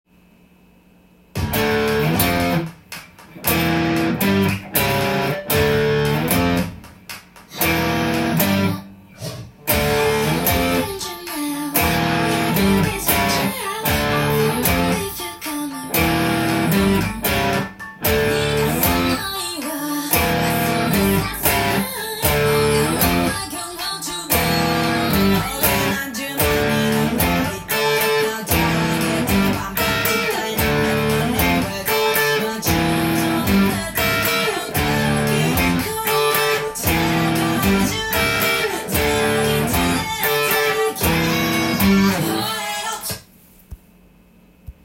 音源に合わせて譜面通り弾いてみました
かなり攻撃的でカッコいい曲です。
ひたすらDmとEdimのコードを繰り返すだけですが